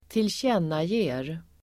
Uttal: [²tiltj'en:aje:r]